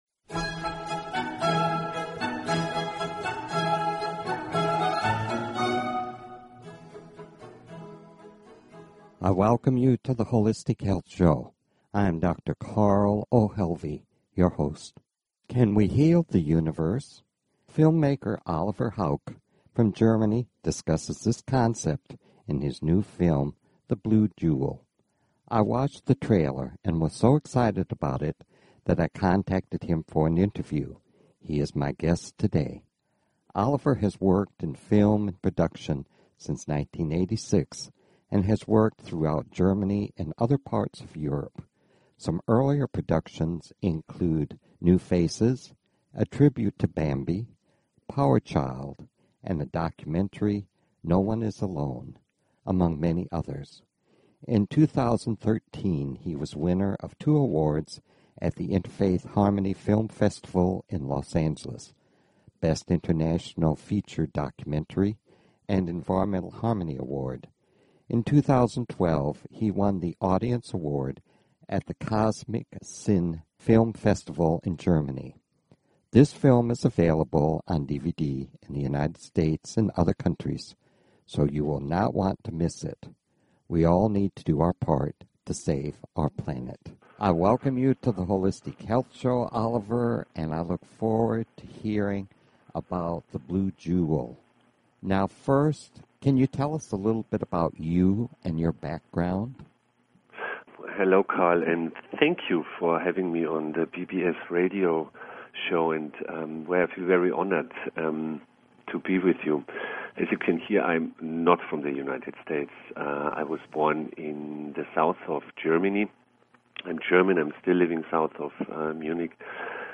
This interview will broadcast onext Saturday and you will not want to miss it..